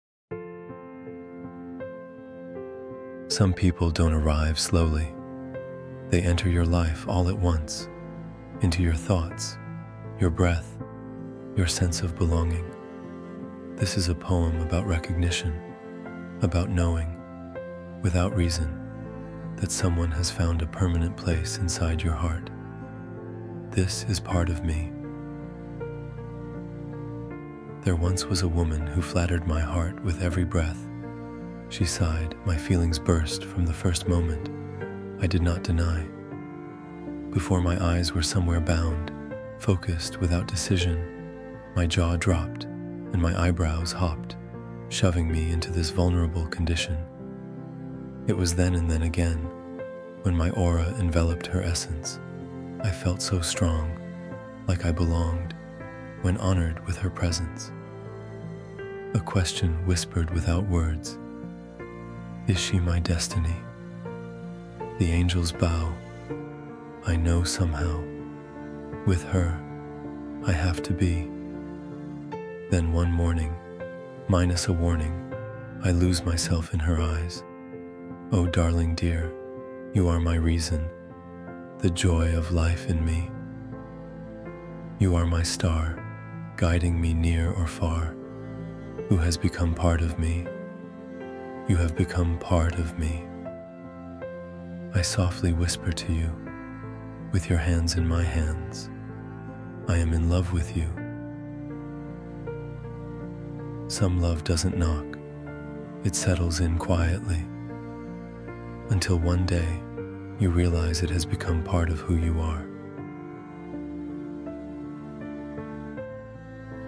Part of Me — a romantic spoken word poem about destiny, closeness, and falling deeply in love.
part-of-me-romantic-spoken-word-love-poem.mp3